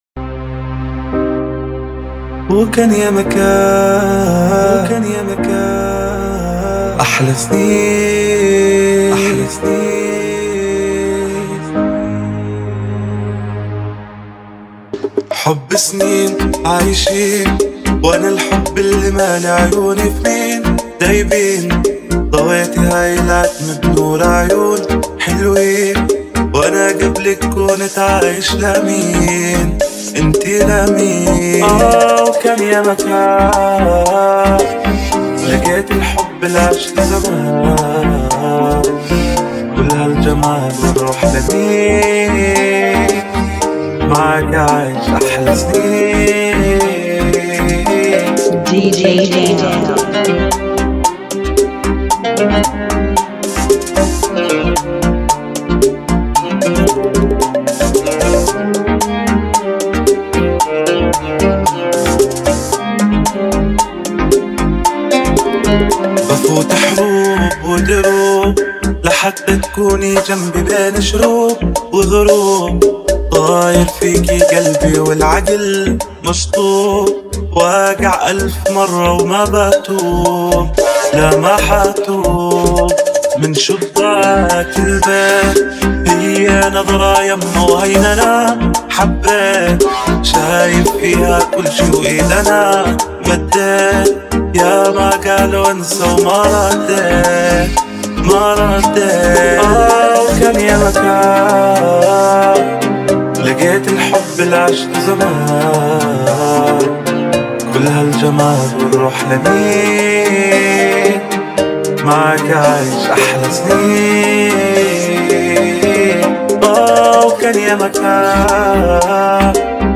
112 BPM
Genre: Bachata Remix